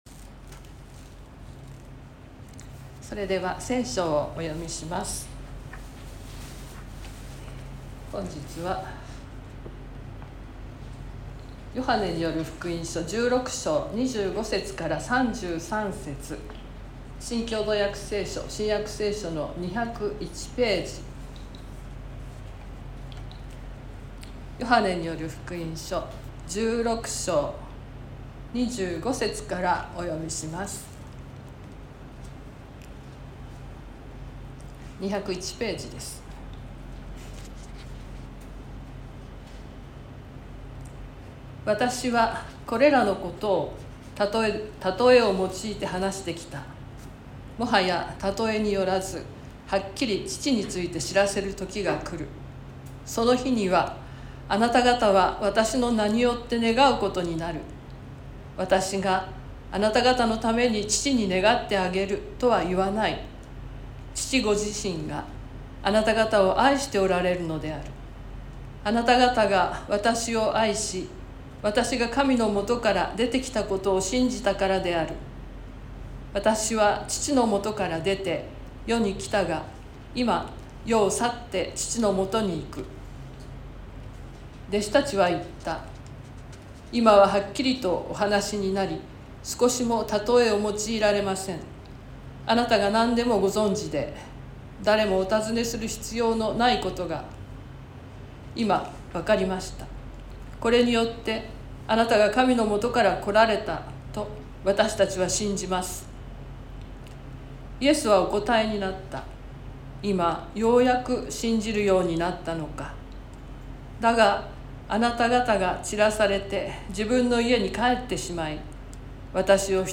説教アーカイブ。
Youtubeで直接視聴する 音声ファイル 礼拝説教を録音した音声ファイルを公開しています。